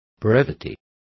Complete with pronunciation of the translation of brevity.